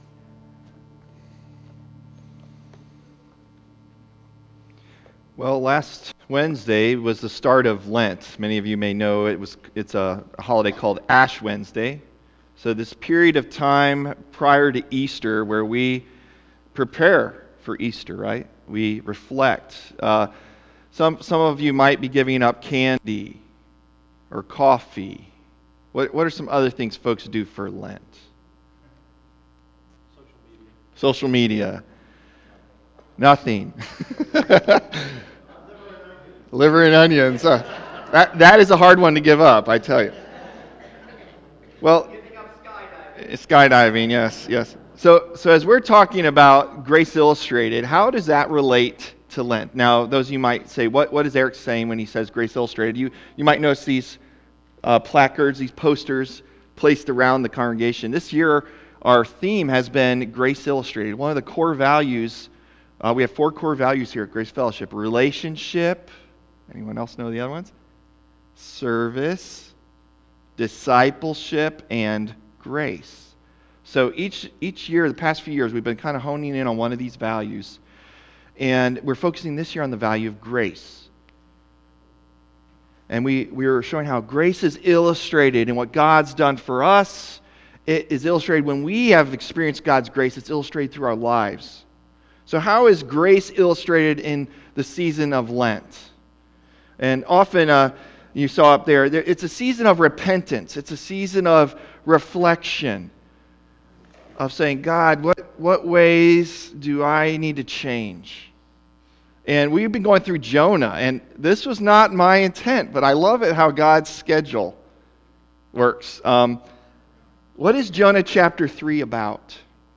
March 5 Sermon | A People For God